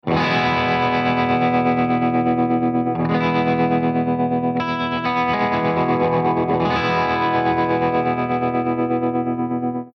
032_BUCKINGHAM_TREMOLO2_P90